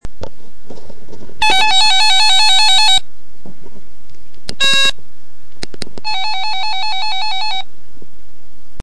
Záznam z amatérské činnosti je v následující nahrávce.
80m pásmo červen 2009. (zvuk MP3 )